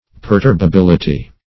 Search Result for " perturbability" : The Collaborative International Dictionary of English v.0.48: Perturbability \Per*turb`a*bil"i*ty\, n. The quality or state of being perturbable.
perturbability.mp3